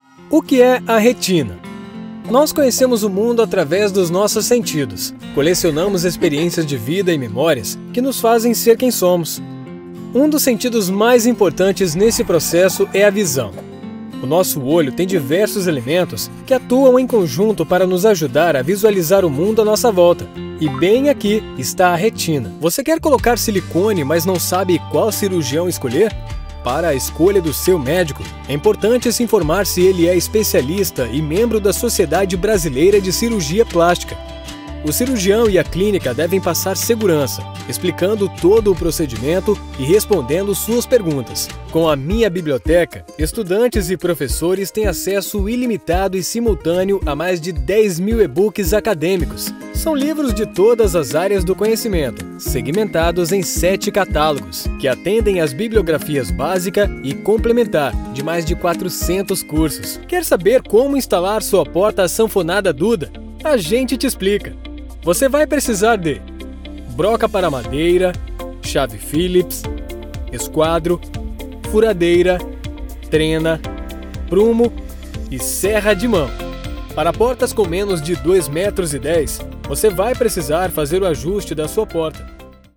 Masculino
Voz Jovem 01:22
Além de equipamentos profissionais devidamente atualizados, todas as locuções são gravadas em cabine acústica, resultando em um áudio limpo e livre de qualquer tipo de interferência.